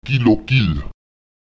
Kommt in einem Wort das Satzzeichen Lith () vor, so wird die diesem Zeichen unmittelbar folgende Silbe betont: